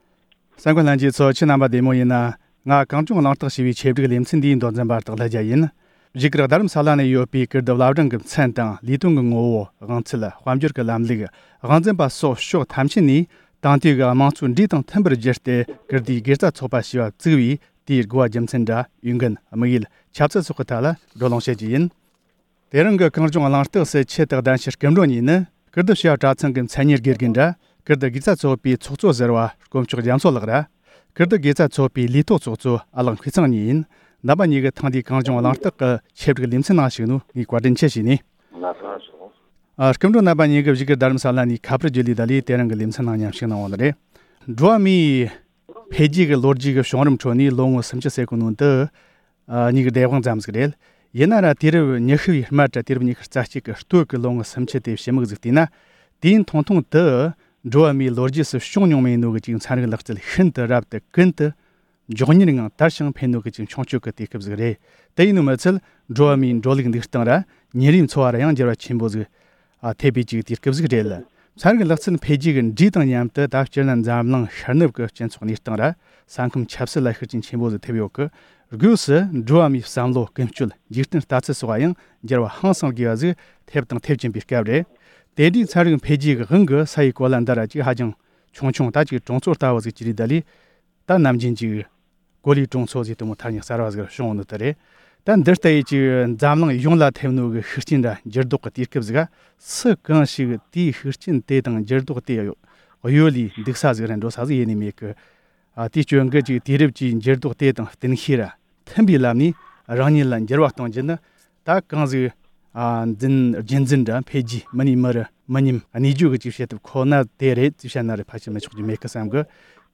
བཞུགས་སྒར་དུ་ཡོད་པའི་ཀིརྟིའི་བླ་བྲང་གི་མཚན་དང་ལས་དོན་དེ་བཞིན་དབང་ཚད་དང་དཔལ་འབྱོར་སོགས་ཀྱི་ཐད་བགྲོ་གླེང་།